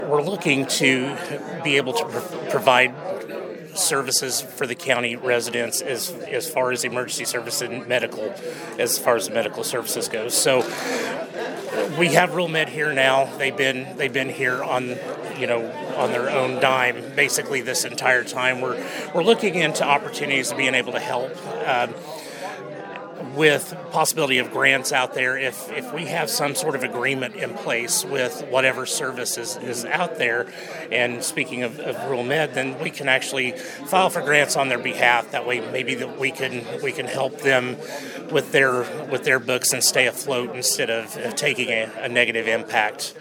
He talks about the goals of the committee.